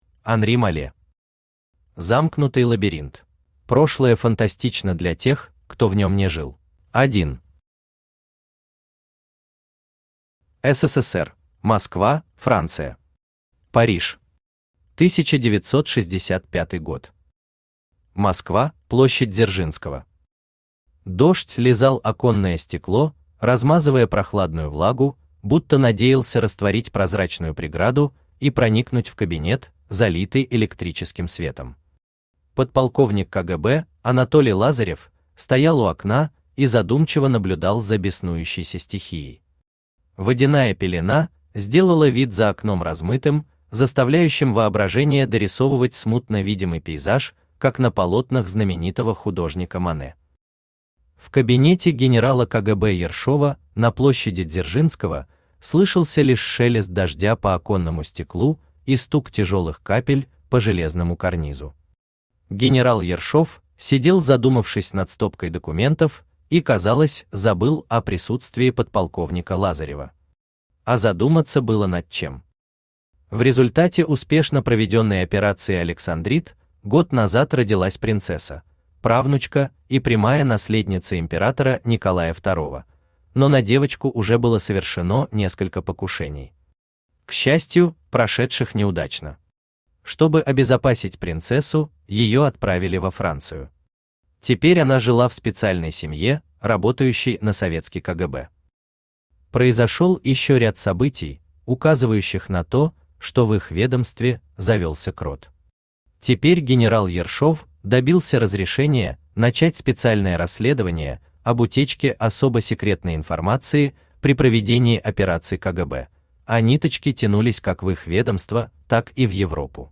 Аудиокнига Замкнутый лабиринт | Библиотека аудиокниг
Aудиокнига Замкнутый лабиринт Автор Анри Малле Читает аудиокнигу Авточтец ЛитРес.